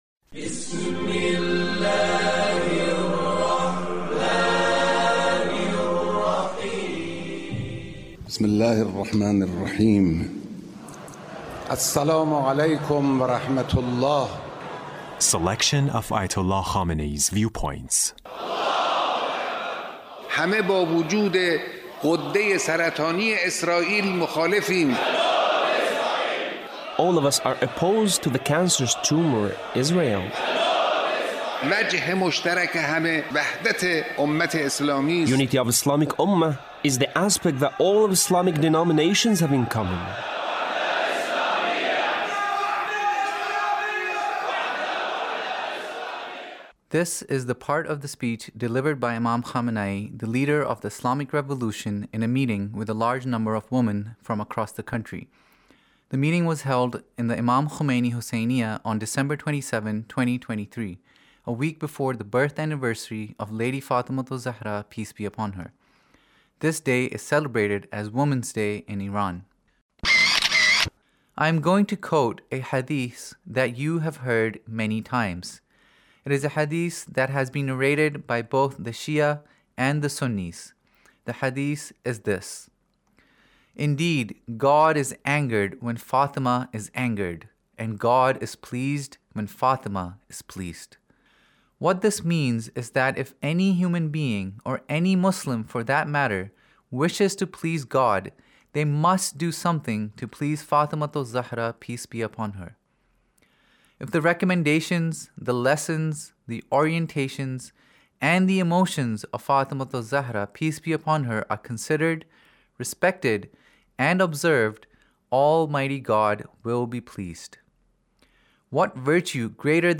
Leader's Speech about , in a meeting with Ladies